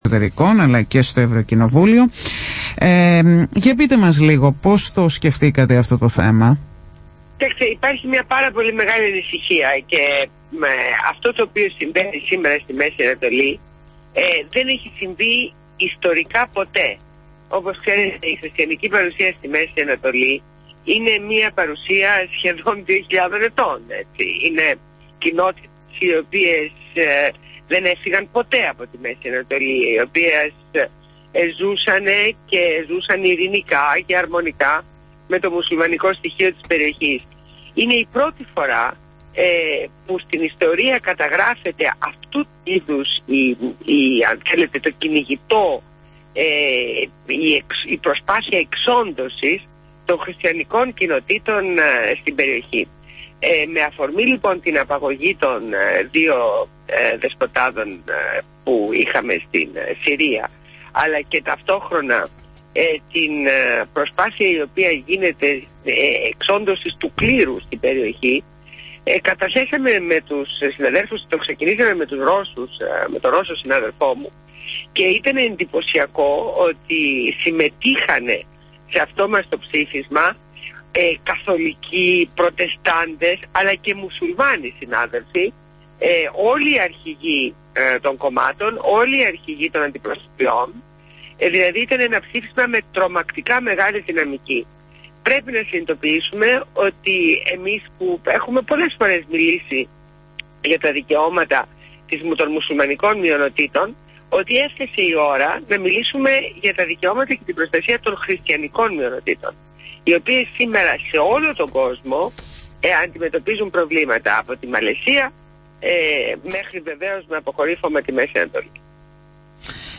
Συνέντευξη στο ραδιόφωνο της Εκκλησίας